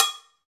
PRC XCOWB1LL.wav